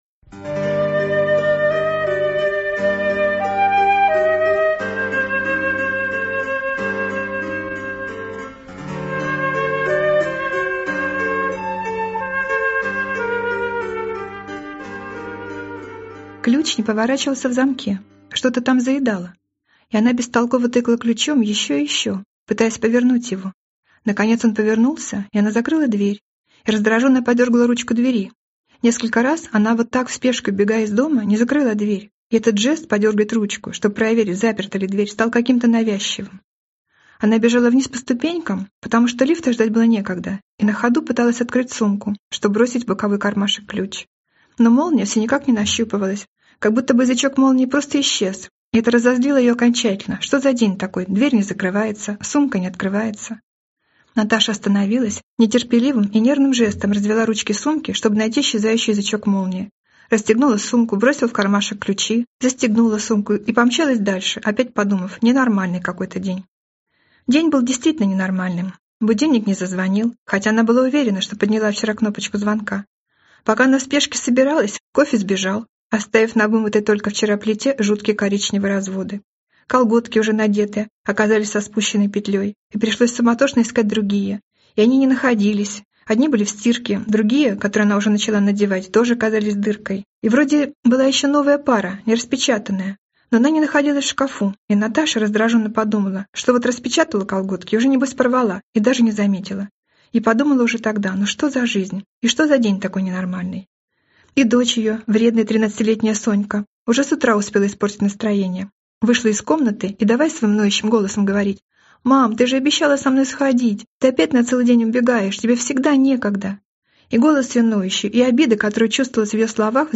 Аудиокнига Не беги…
Прослушать и бесплатно скачать фрагмент аудиокниги